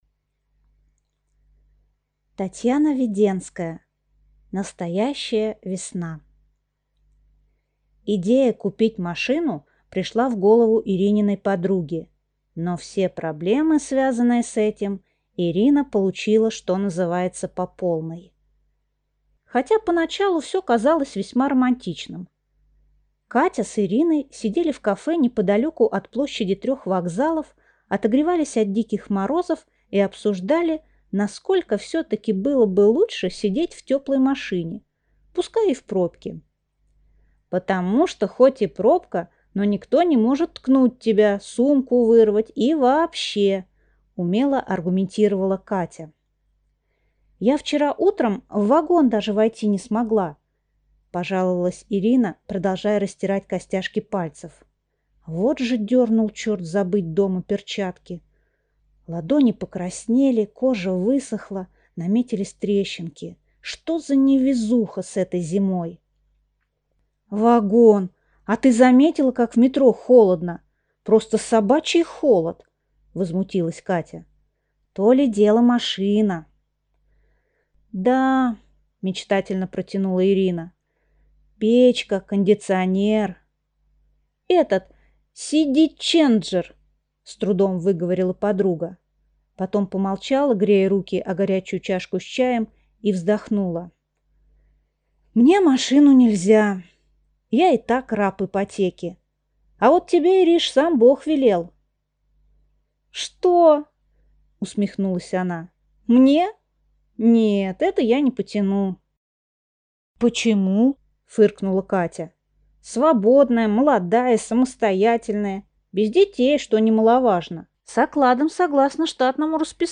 Аудиокнига Настоящая весна | Библиотека аудиокниг
Прослушать и бесплатно скачать фрагмент аудиокниги